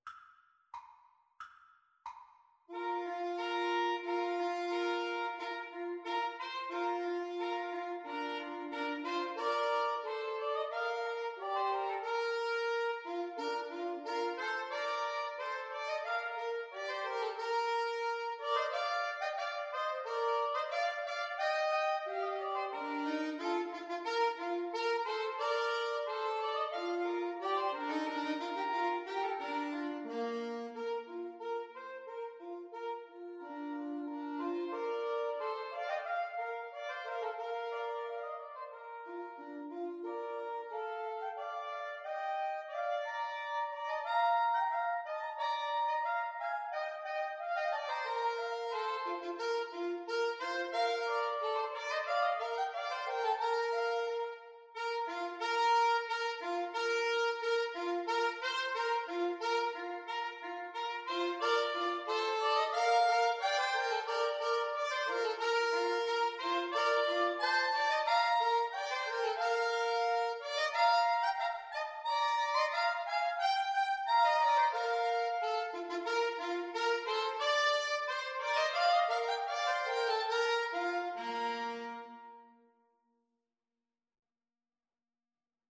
Soprano SaxophoneAlto SaxophoneTenor Saxophone
Bb major (Sounding Pitch) (View more Bb major Music for Woodwind Trio )
March ( = c. 90)